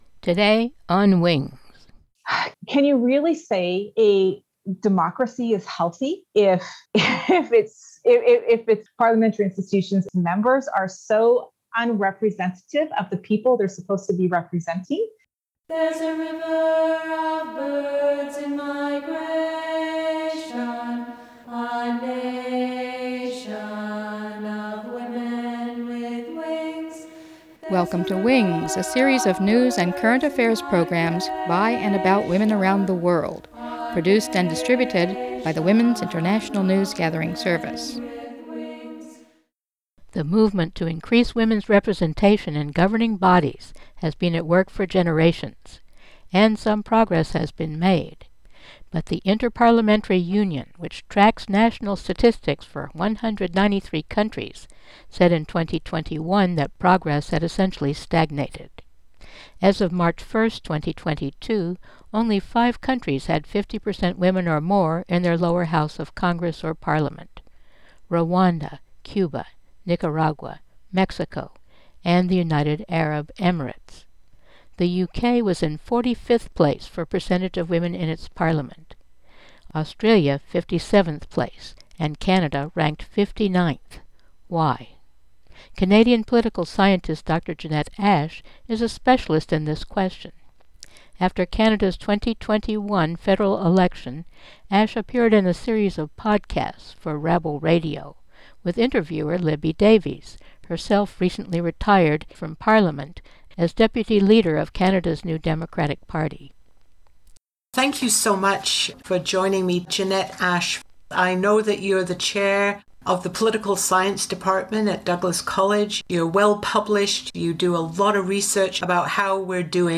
Libby Davies interviews Canadian political scientist